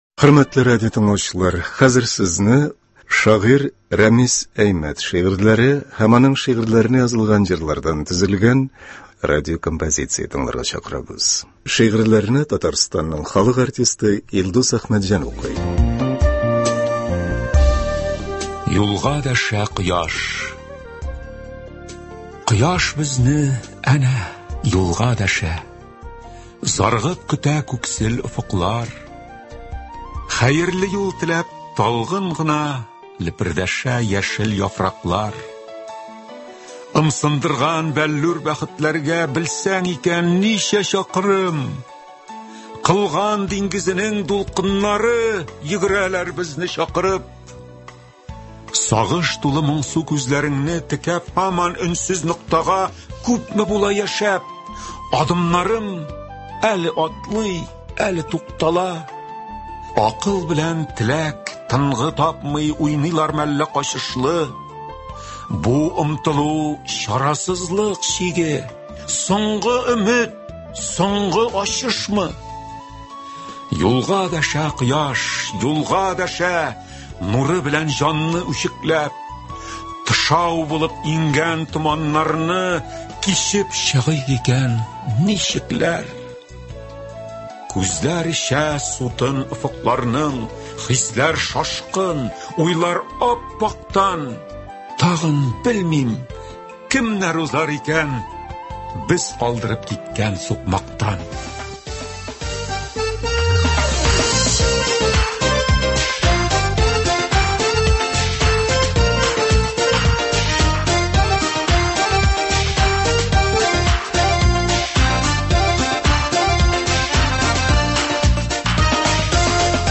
Көндезге концерт.